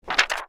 paper_putdown4.wav